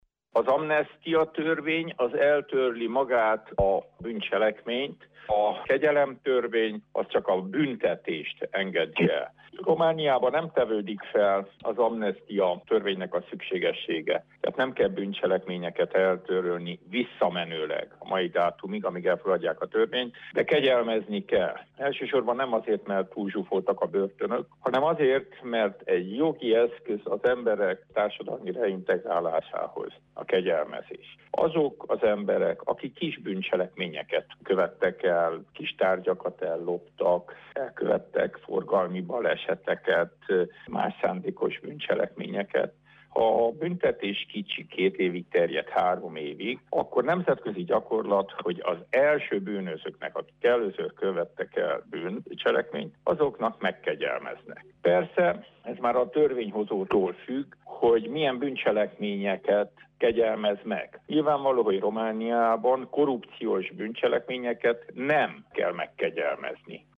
Az amnesztia vagy megkegyelmezés nem oldja meg a romániai börtönök túlzsúfoltságának kérdését, szükség van új büntetés-végrehajtási intézetek építésére – véli Frunda György ügyvéd, aki azt is elmondta szerkesztőségünknek, hogy újabb büntetéseket róhat ki az Emberi Jogok Európai Bírósága a fegyházakban uralkodó állapotok miatt. Frunda Györgyöt hallják.